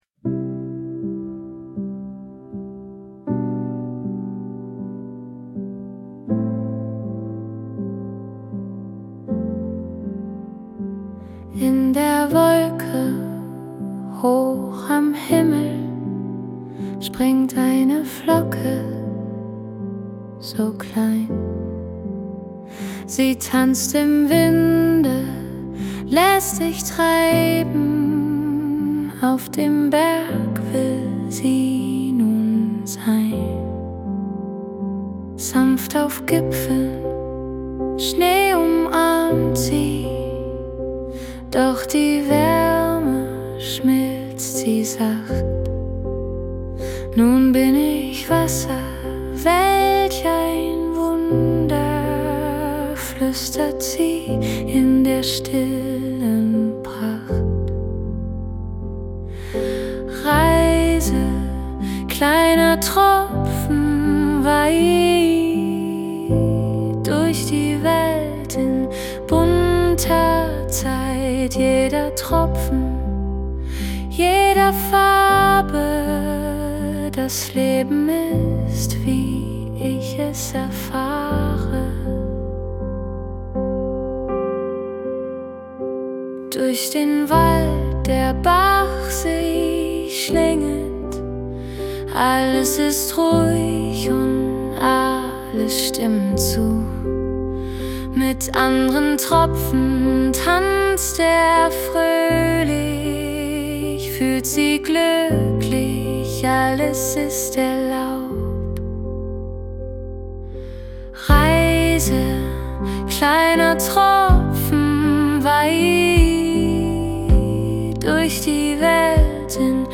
Das Schlaflied zur Geschichte